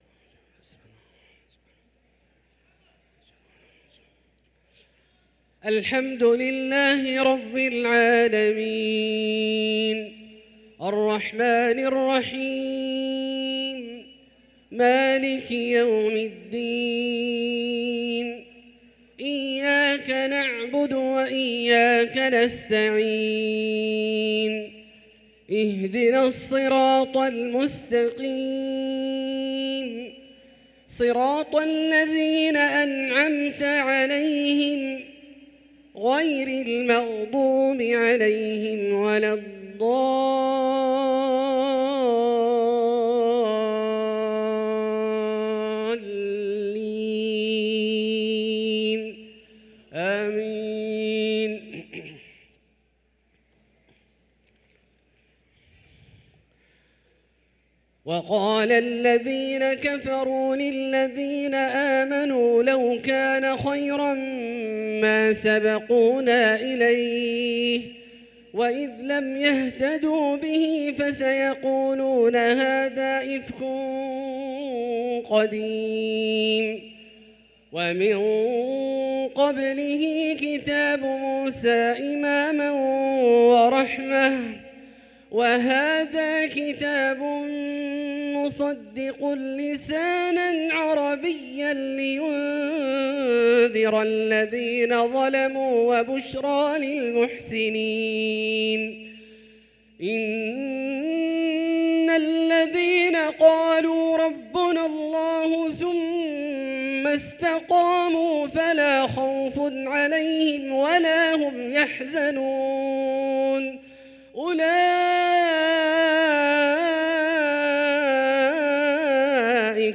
صلاة العشاء للقارئ عبدالله الجهني 24 جمادي الآخر 1445 هـ